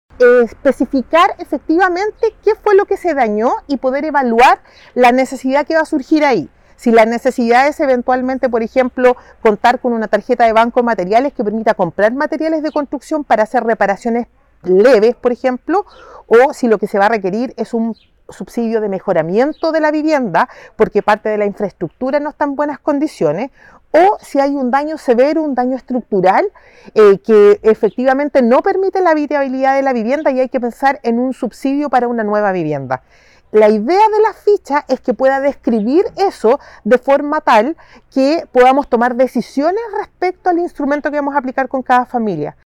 SERVIU-viviendas-danadas_directora-2.mp3